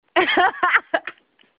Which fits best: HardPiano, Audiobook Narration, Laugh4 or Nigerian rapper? Laugh4